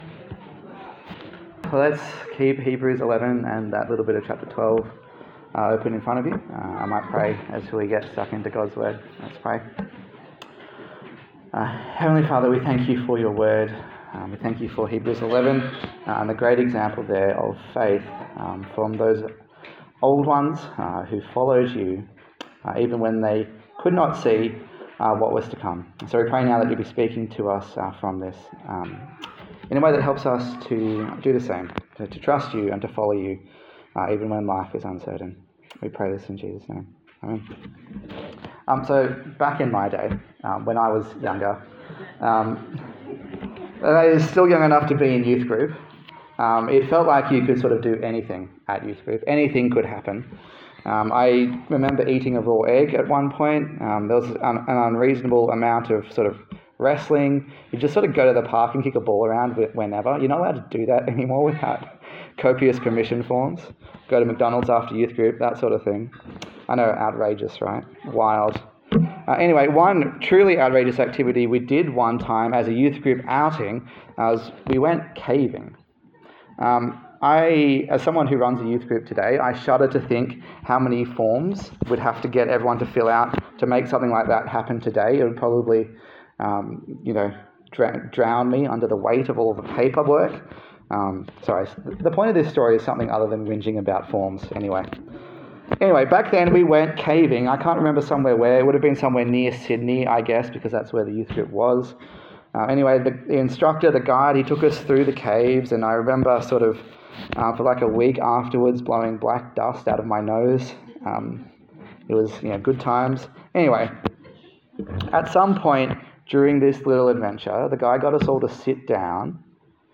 A sermon
Hebrews Passage: Hebrews 11:1-12:3 Service Type: Sunday Morning